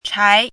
“侪”读音
chái
侪字注音：ㄔㄞˊ
国际音标：tʂʰĄi˧˥
chái.mp3